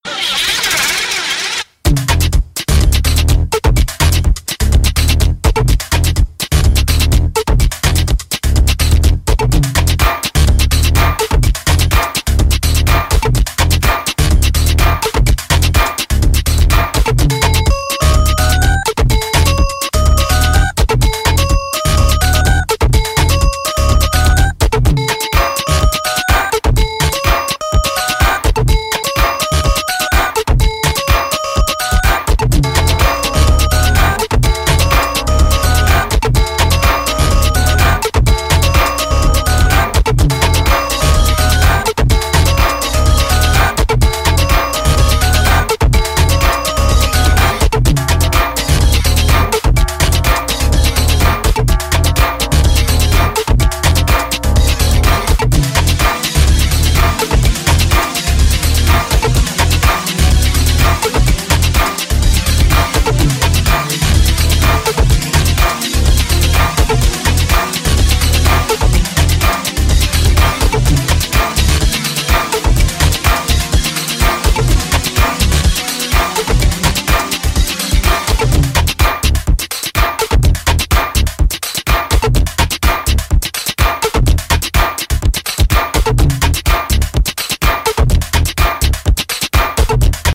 House Techno Bass